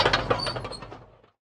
tank-tracks-stop-1.ogg